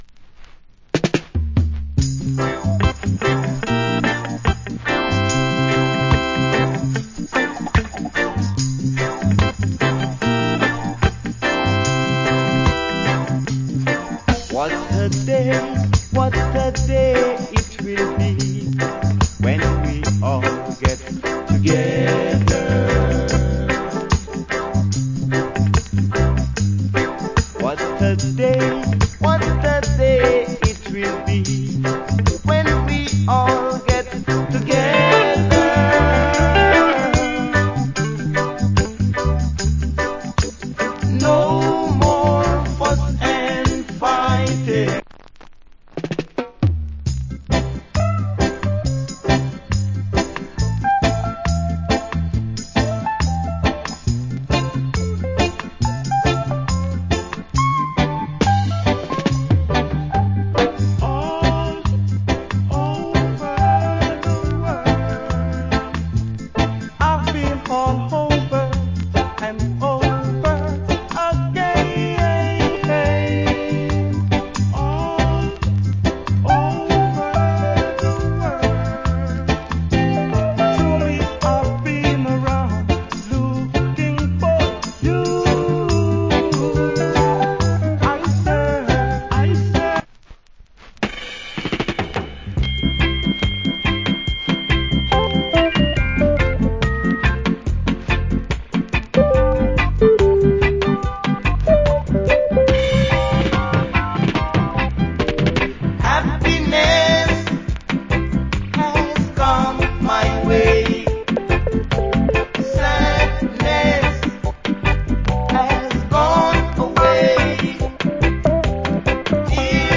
ROOTS ROCK
Good 70's Roots Rock Reggae LP.
70's